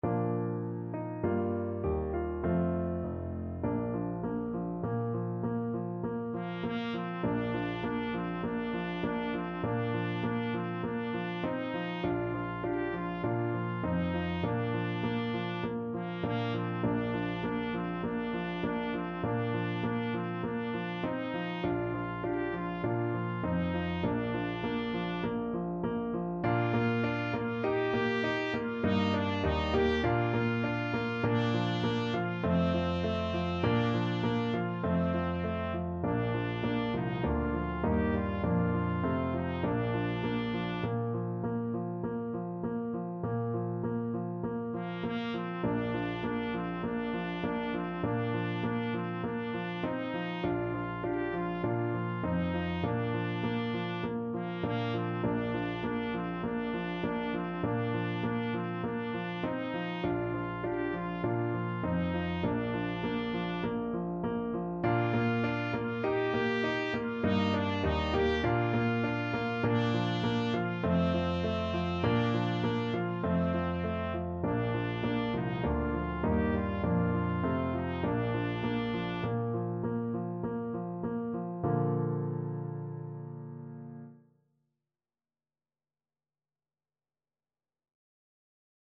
Christmas Christmas Trumpet Sheet Music Es ist fur uns eine Zeit angekommen
Trumpet
4/4 (View more 4/4 Music)
Bb4-G5
Bb major (Sounding Pitch) C major (Trumpet in Bb) (View more Bb major Music for Trumpet )
Moderato
Traditional (View more Traditional Trumpet Music)